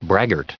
added pronounciation and merriam webster audio
1905_braggart.ogg